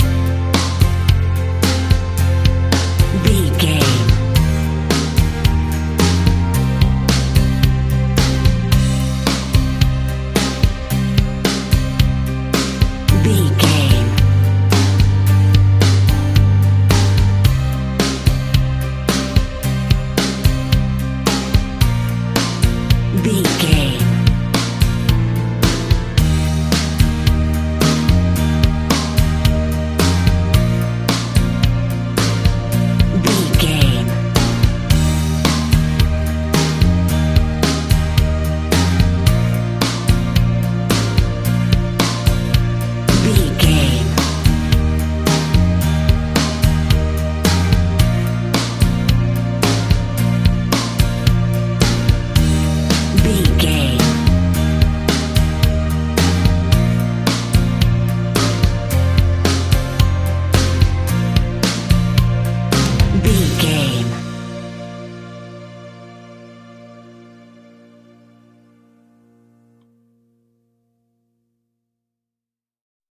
Aeolian/Minor
fun
energetic
uplifting
instrumentals
guitars
bass
drums
organ